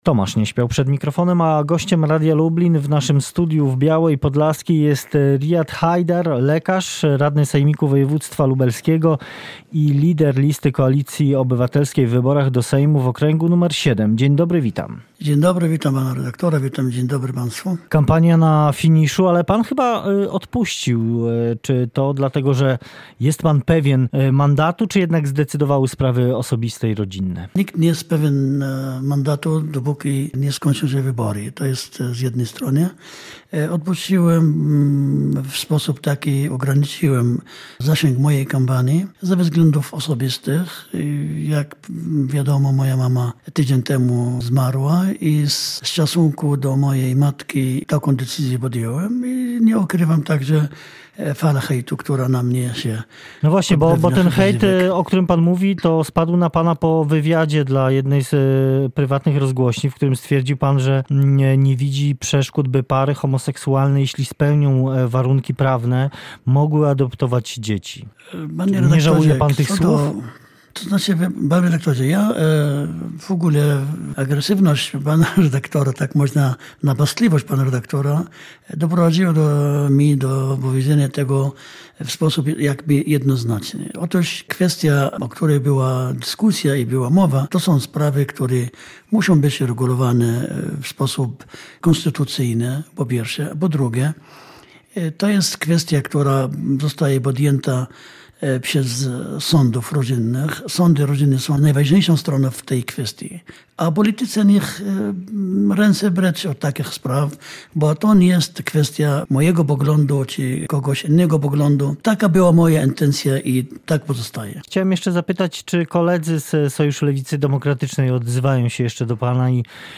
– Nikt nie jest pewien mandatu, dopóki nie skończą się wybory – mówi na antenie Radia Lublin Haidar.